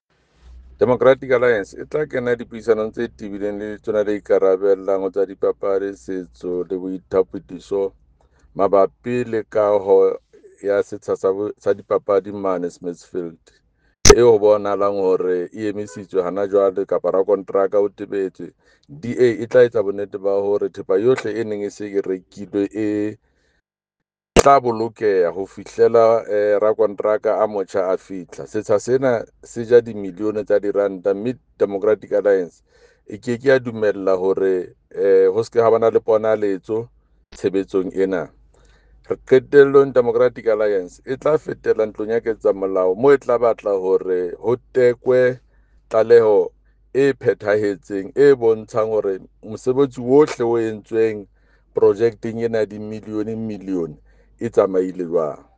Sesotho soundbite by Jafta Mokoena MPL, with images here, here and here